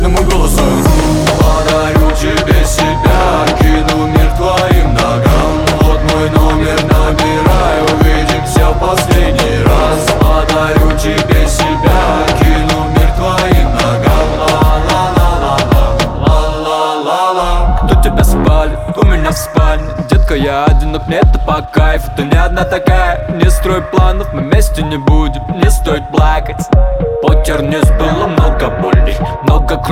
Жанр: Латиноамериканская музыка / Рэп и хип-хоп